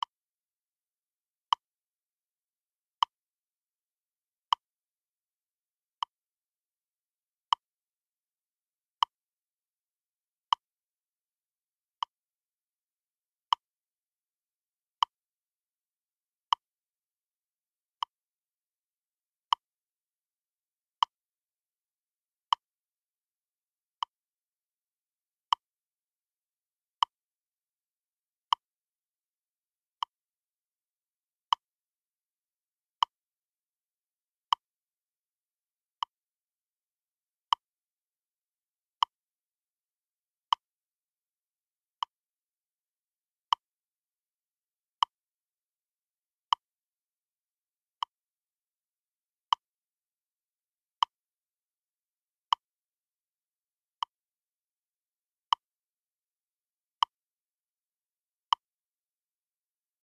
Po prostu ustawiasz metronom na podane tempo i grasz dźwięki równo z klkikiem.
Kliki metronomu
tempo40.mp3